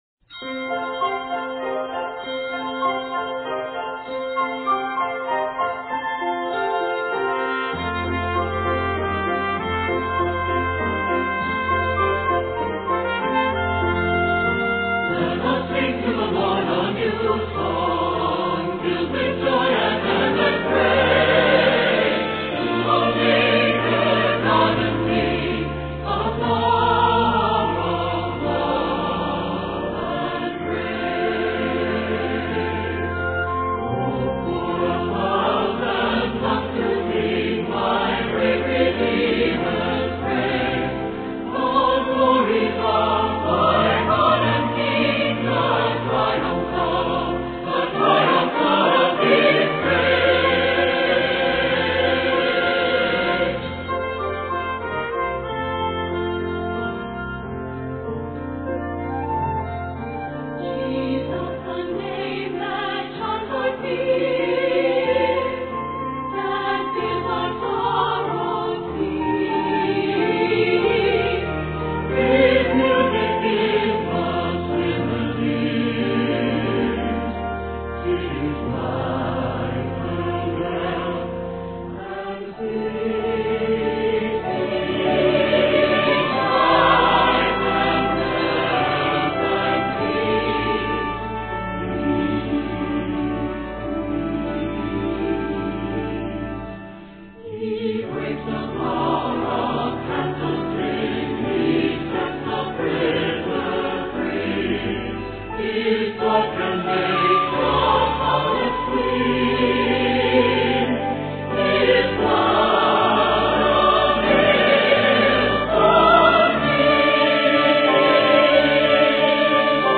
Stirring and energetic throughout